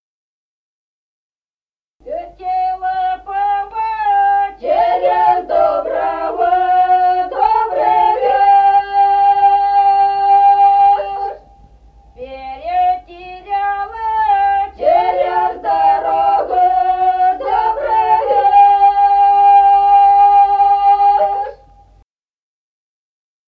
Народные песни Стародубского района «Летела пава», новогодняя щедровная.
запев
подголосник
с. Остроглядово.